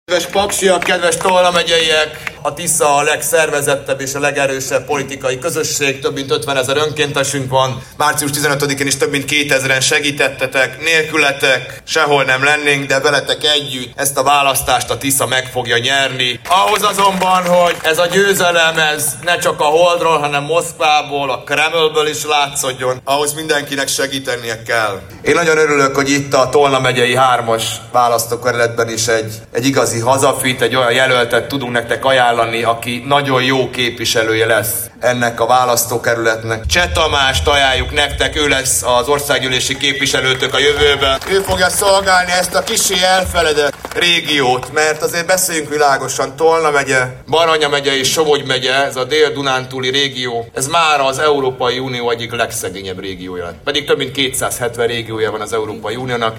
Országjárása keretében Paksra érkezett szerda este Magyar Péter.